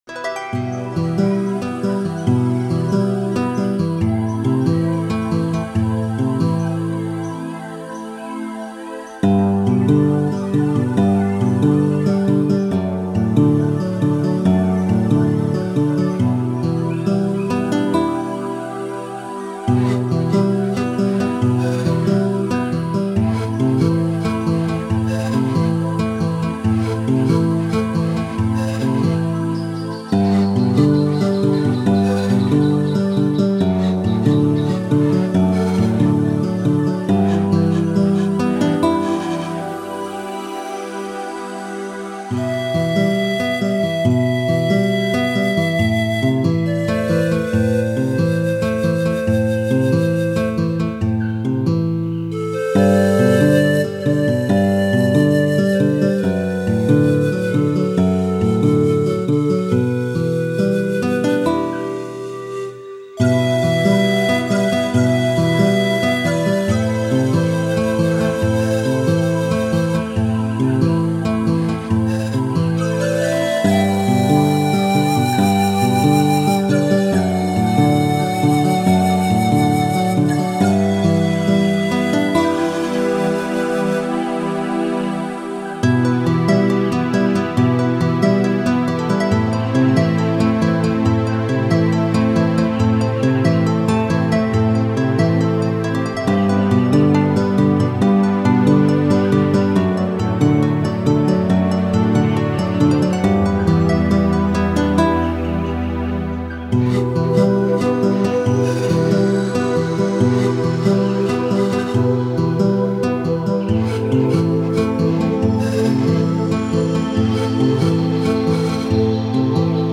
LA MUSIQUE D'AMBIANCE